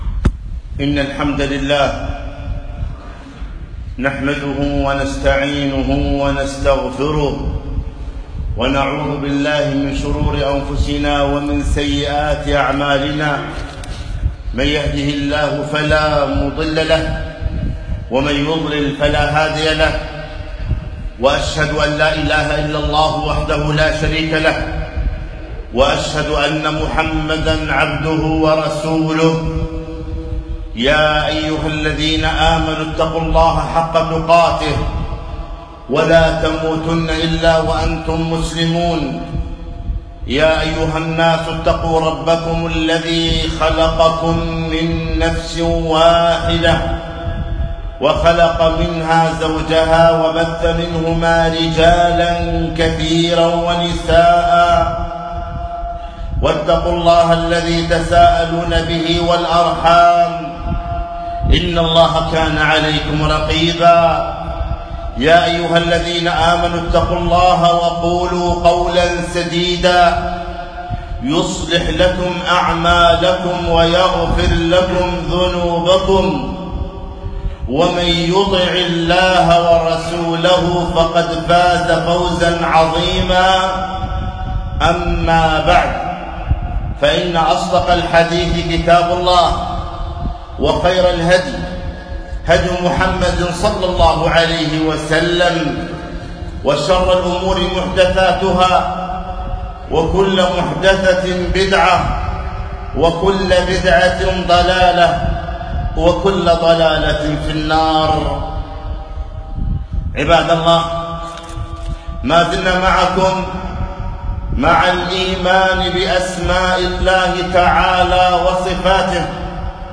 خطبة - تنبيه الغيور إلى اسم الله الشكور